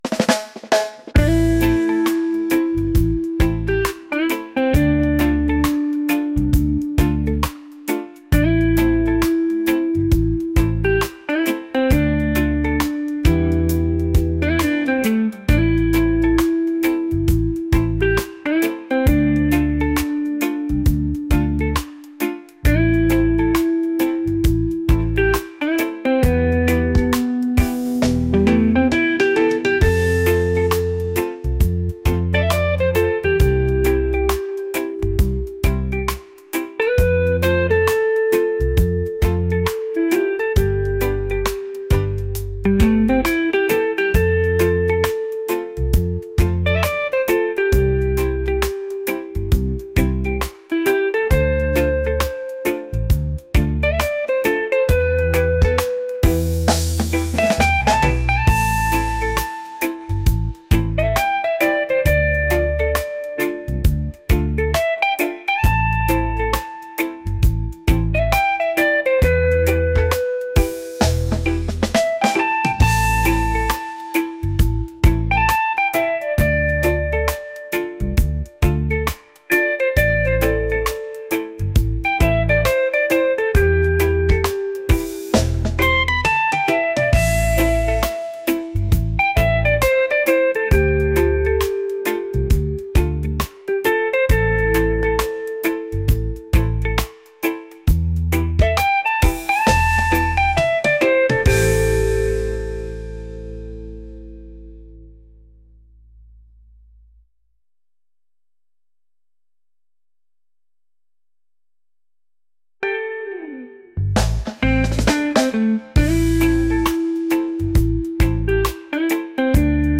reggae | soul | laid-back